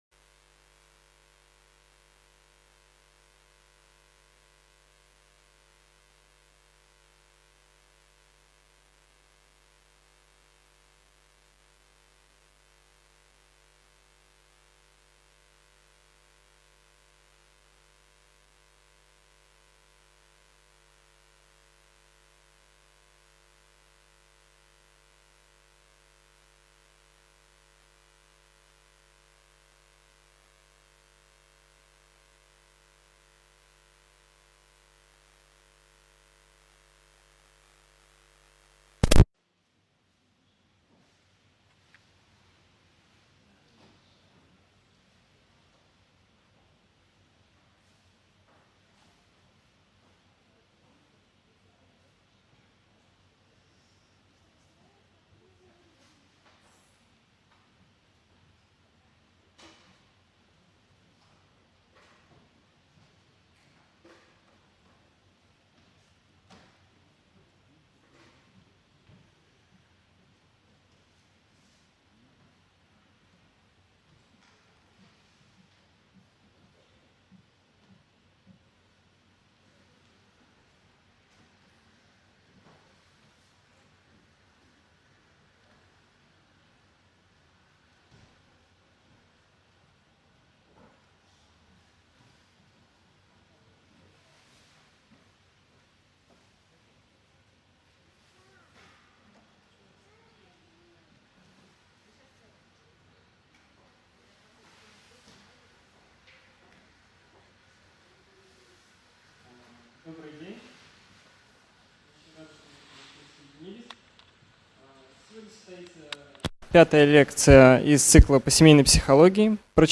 Аудиокнига Возрастные кризисы у ребенка: что знать и как себя вести | Библиотека аудиокниг